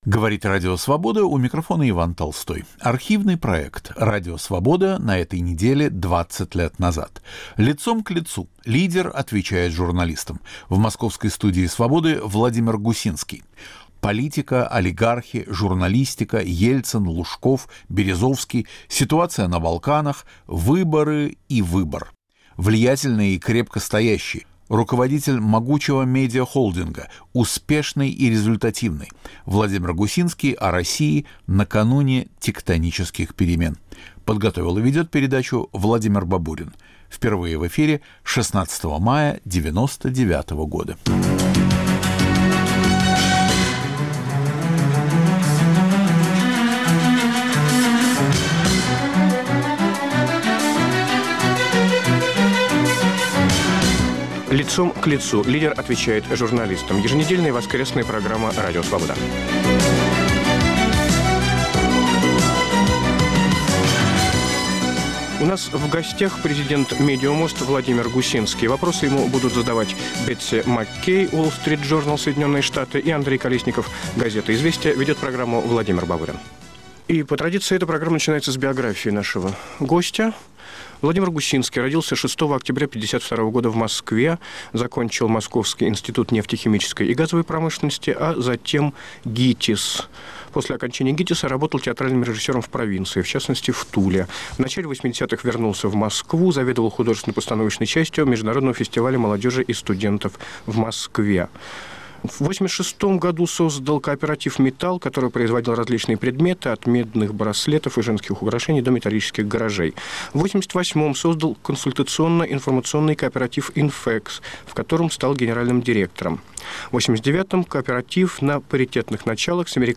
Радио Свобода на этой неделе 20 лет назад. В студии Владимир Гусинский
Архивный проект.